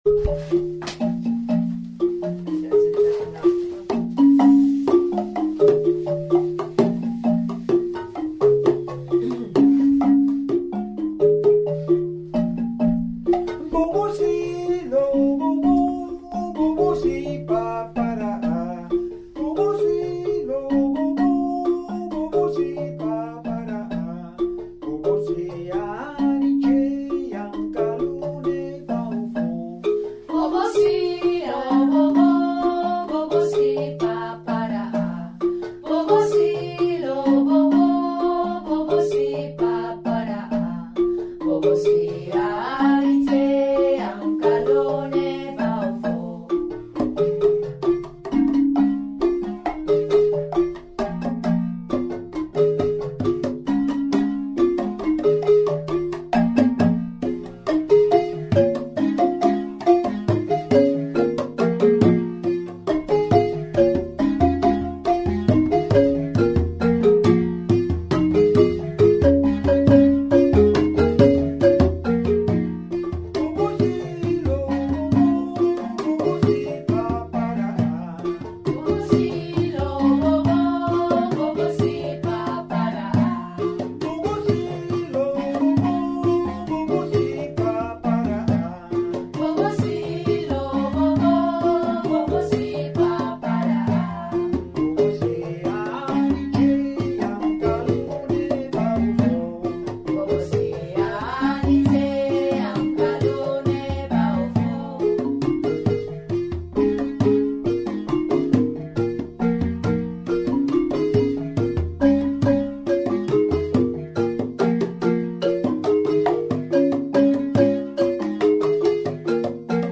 Pentatonische balafoon
Bobo Sin wordt gespeeld en gezongen tijdens een repetitie van ‘Dondory’